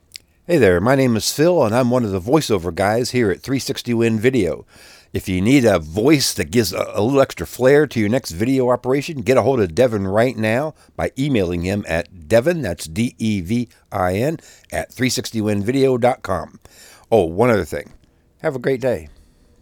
Voice Talent
Passionate, warm, attractive voice, committed to high-quality results.